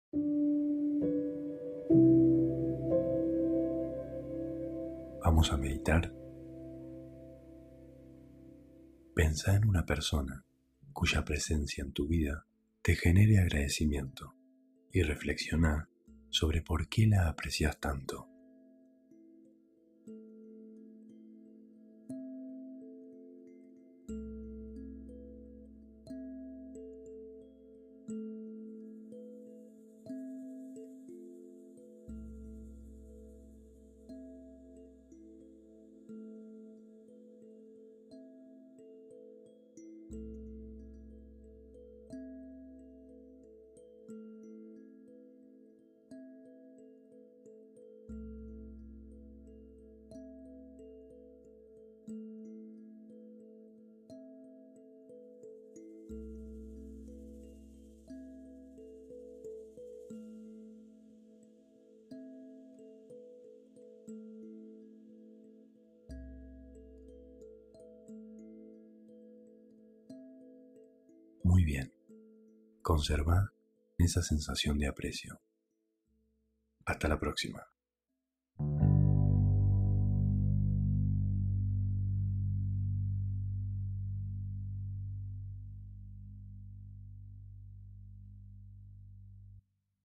Meditación de 1 minuto para reflexionar.